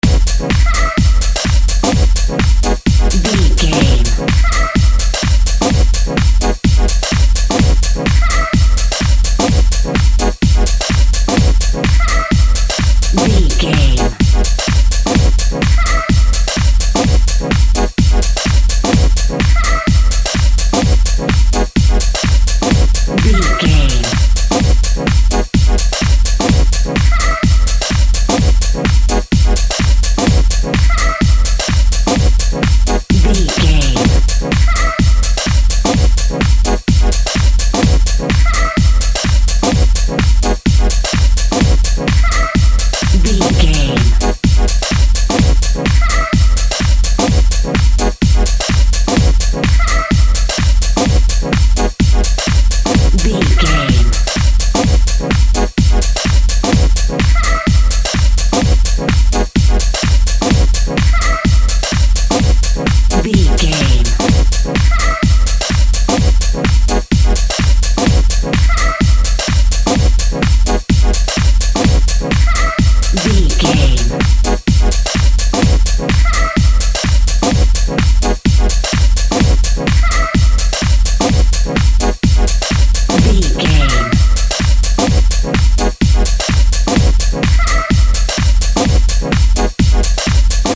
Ionian/Major
groovy
uplifting
futuristic
driving
energetic
repetitive
synthesiser
drum machine
electro house
synth lead
synth bass